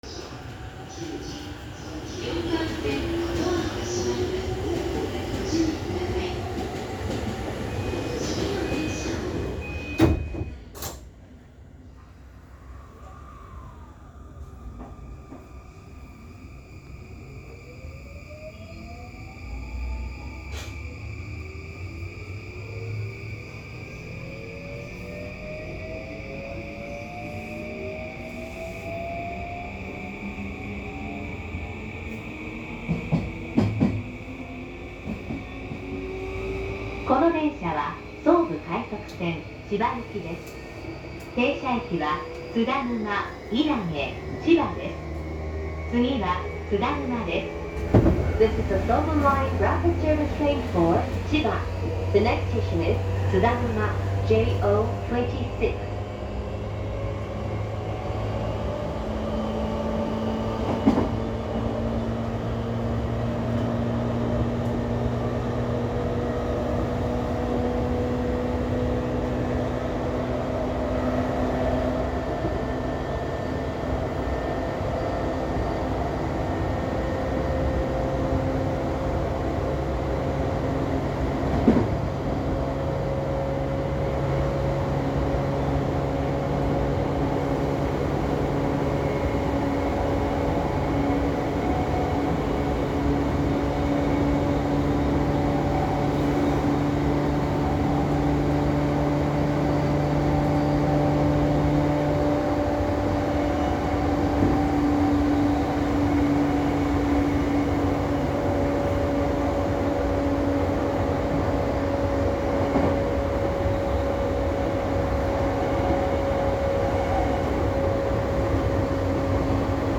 三菱SiCをJR東日本で本格採用したのがE235系で、これまでの車両とは明らかに音が異なります。
・E235系1000番台走行音
【総武快速線】船橋→津田沼
加速度が異なる程度で音は↑と変わりません。